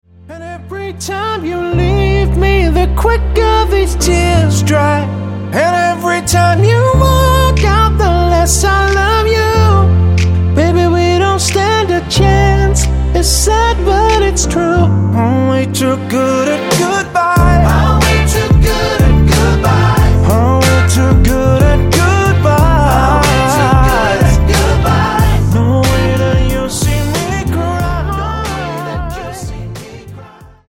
Tonart:Dm Multifile (kein Sofortdownload.
Die besten Playbacks Instrumentals und Karaoke Versionen .